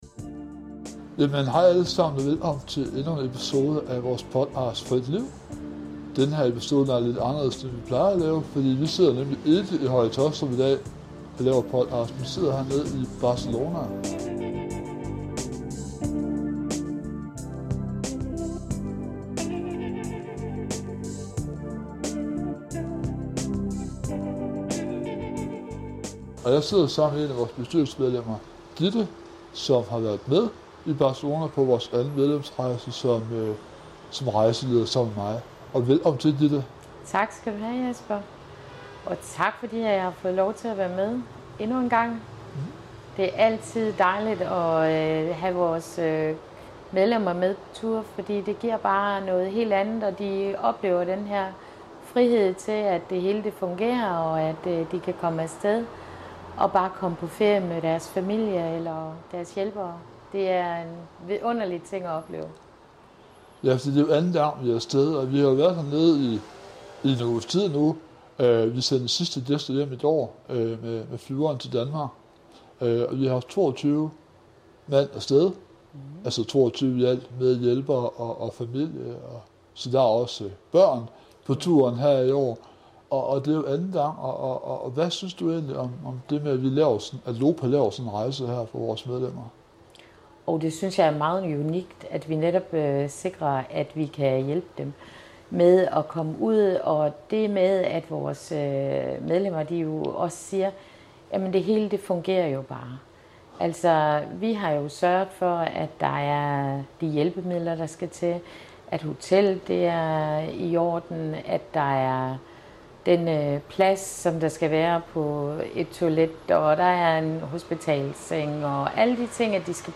Podcasten er optaget på sidste dag i Barcelona inden de to guider rejste hjem til Danmark igen.